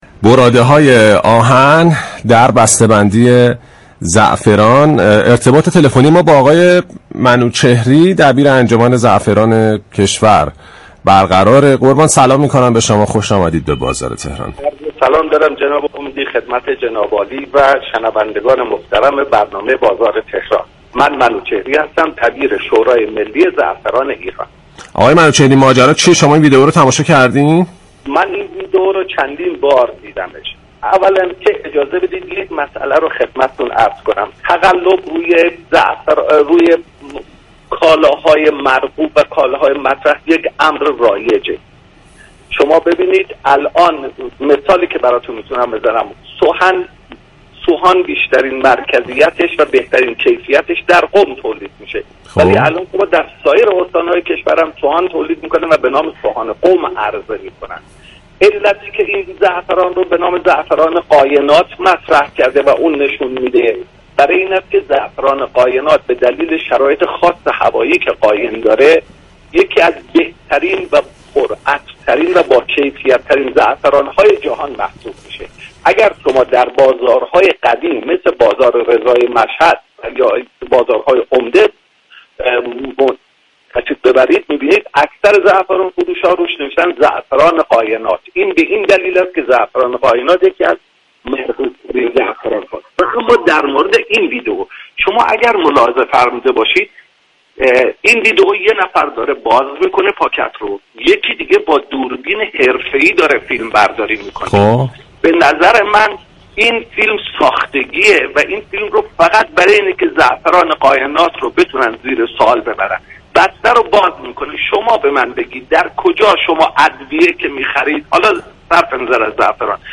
در گفت‌وگو با بازار تهران رادیو تهران